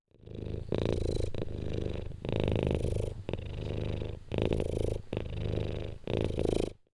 purr.mp3